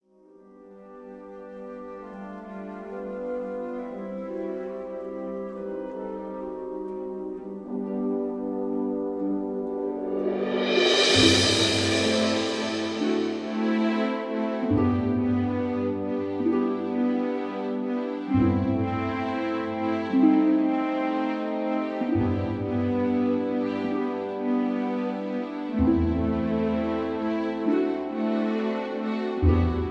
(Key-G)